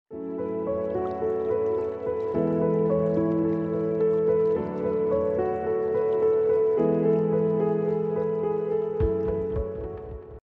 A tranquil night beneath a sound effects free download
A tranquil night beneath a star-studded sky where the stone bridge connects the riverbanks, and the water flows gently in silence, embraced by still trees that complete the beauty of the scene.